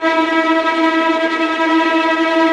VIOLINT F#-L.wav